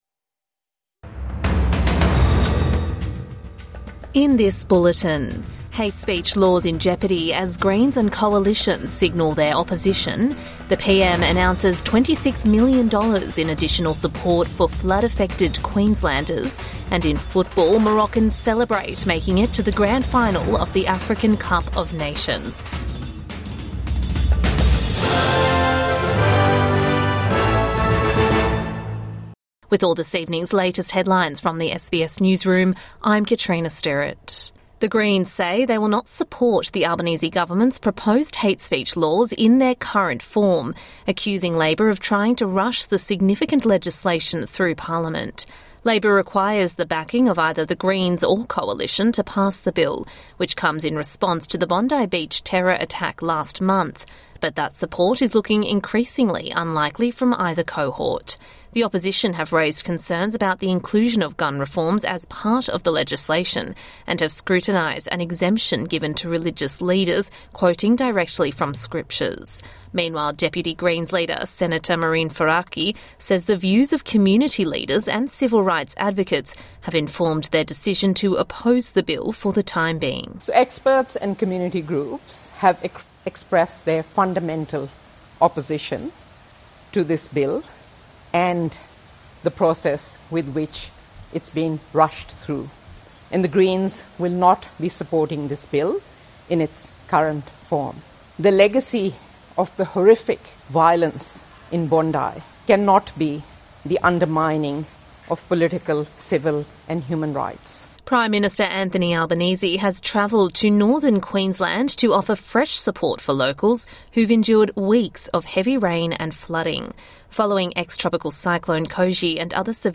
PM announces $26 million in support for flood-affected Queenslanders | Evening News Bulletin 15 January 2026